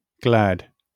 IPA/ɡlæd/, SAMPA/ɡl{d/